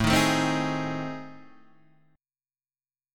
AmM7b5 chord {5 3 6 5 4 4} chord